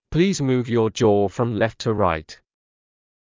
ﾌﾟﾘｰｽﾞ ﾑｰﾌﾞ ﾕｱ ｼﾞｬｰ ﾌﾛﾑ ﾚﾌﾄ ﾄｩ ﾗｲﾄ